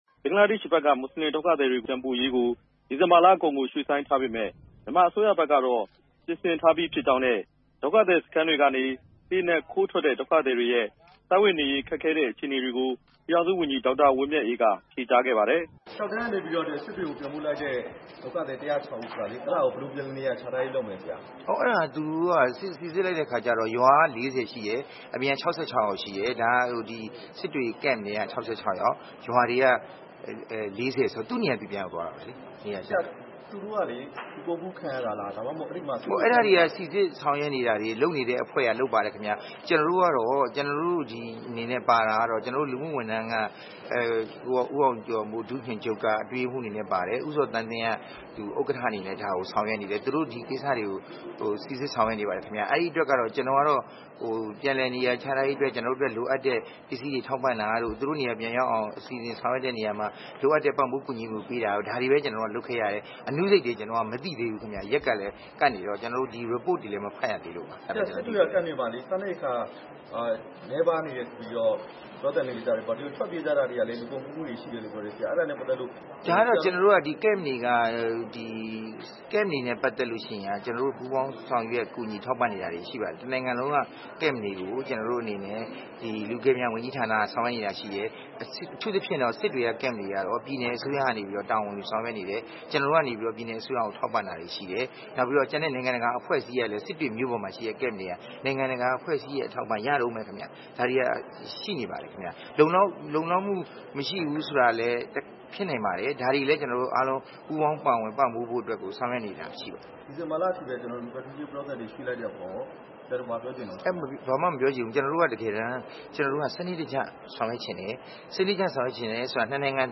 နေပြည်တော်က မြတ်မင်္ဂလာ ဟိုတယ်မှာ ဒီနေ့ ညနေပိုင်းက ကျင်းပခဲ့တဲ့ အပြည်ပြည်ဆိုင်ရာ ကလေးများနေ့ အခမ်းအနားကို တက်ရောက်လာတဲ့ UEHRD ဒုတိယ ဥက္ကဋ္ဌ နဲ့ လူမှုဝန်ထမ်း ကယ်ဆယ်ရေးနဲ့ ပြန်လည်နေရာ ချထားရေး ဝန်ကြီးဌာန ပြည်ထောင်စုဝန်ကြီး ဒေါက်တာ ဝင်းမြတ်အေး ကို RFA က မေးမြန်းရာမှာ အဲဒီလို ဖြေကြားလိုက်တာဖြစ်ပါတယ်။